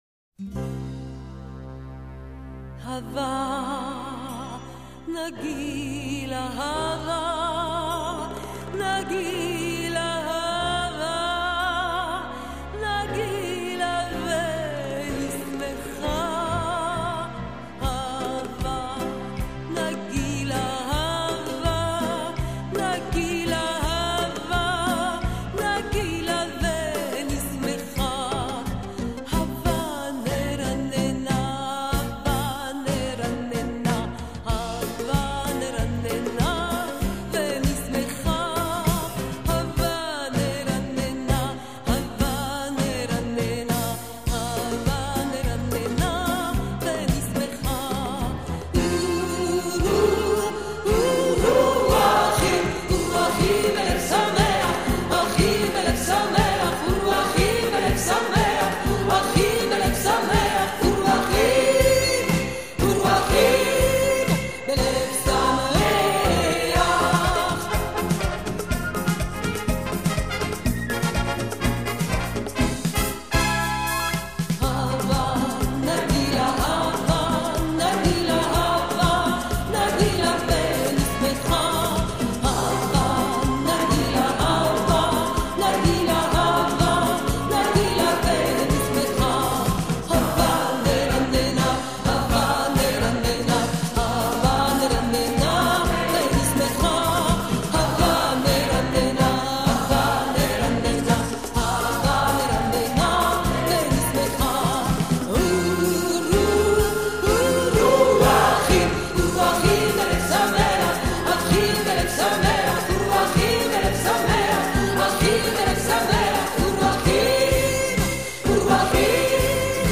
Daarnaast heeft de Amerikaans/Europees-Joodse muziek typische kenmerken zoals het gebruik van veel blaasinstrumenten (vooral klarinet), een sterk geaccentueerd metrum, het gebruik van syncopen en vooral ook de afwisseling in tempo binnen een muziekstuk: traag beginnen, versnellen en weer vertragen.
De sfeer van de muziek kan vrolijk, maar ook juist heel klagelijk zijn.